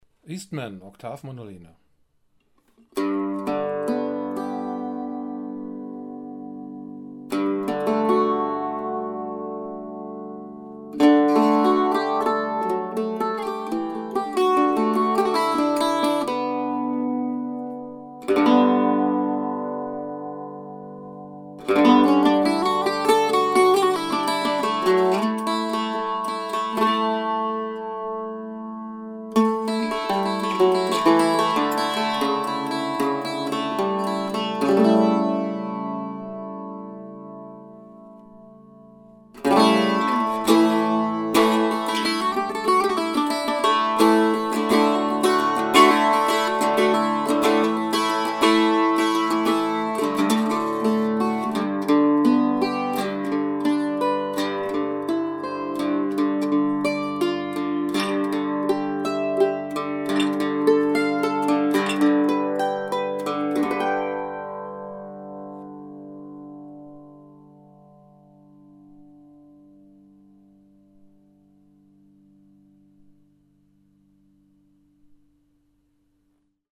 SOUND CLIPS - MANDOLIN
Eastman MDO 305 Octave Mandolin
Oktav Mandoline MDO-305.mp3